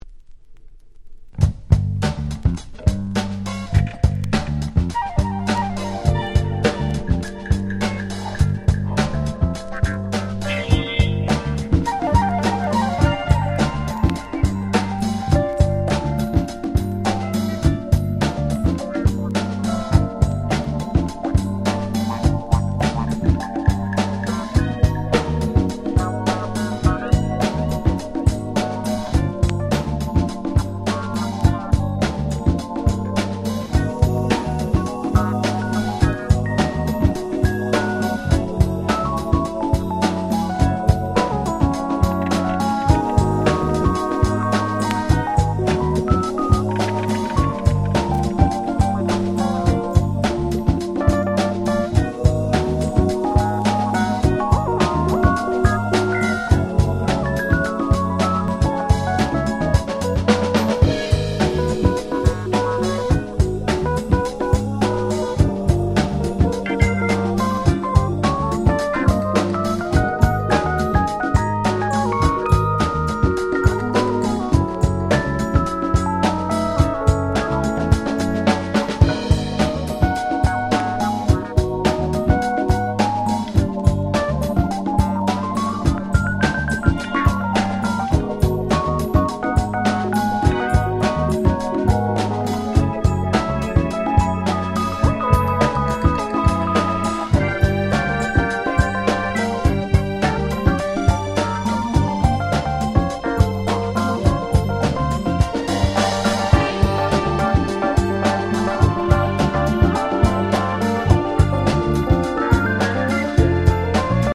92' Super Nice UK Soul / Acid Jazz !!